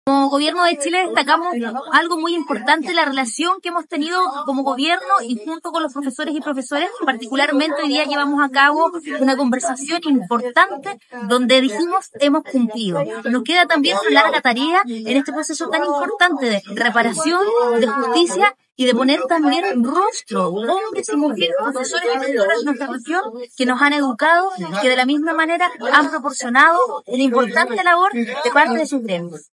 La Delegada Presidencial Regional, Paulina Muñoz, destacó la relación positiva que ha tenido el gobierno con los profesores, resaltando que hoy se llevó a cabo una conversación importante en la que se destacó el cumplimiento de compromisos y enfatizó que, en este proceso de reparación y justicia, también es fundamental reconocer el rostro de los hombres y mujeres, profesores y profesoras, que han dedicado su labor a la educación y al desarrollo de los gremios en la región.